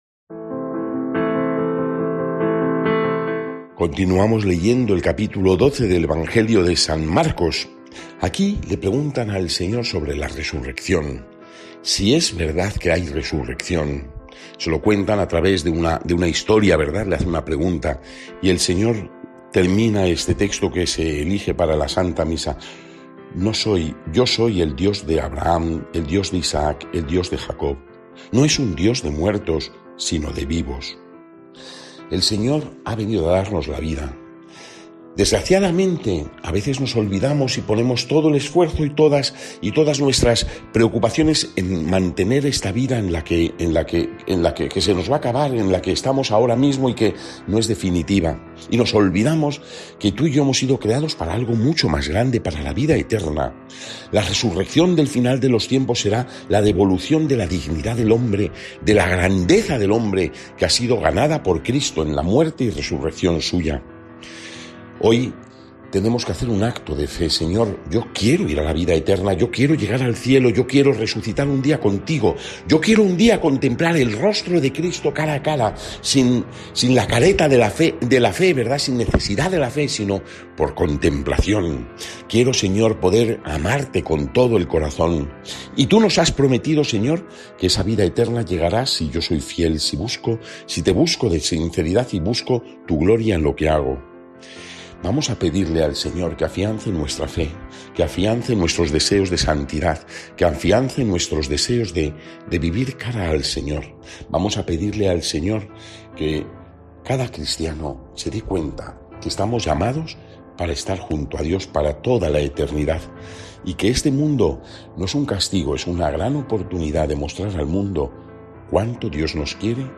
Evangelio según san Marcos (12, 18-27) y comentario